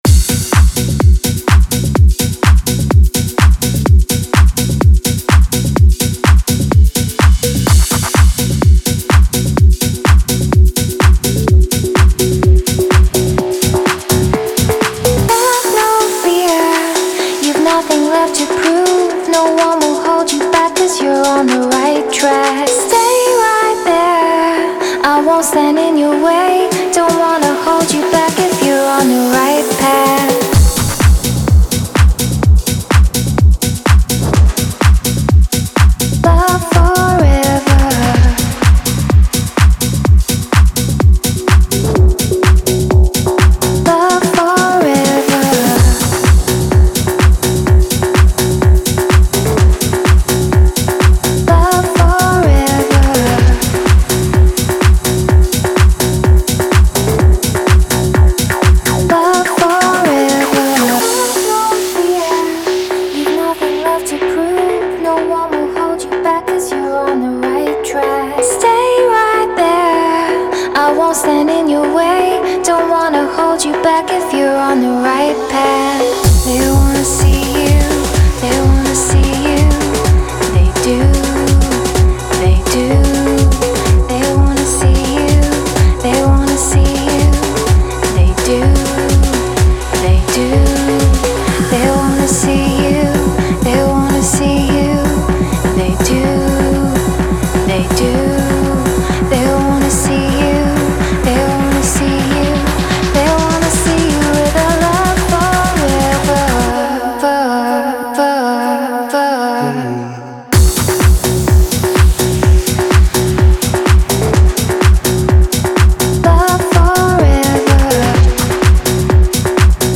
• Жанр: Pop, Dance